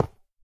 dig1.ogg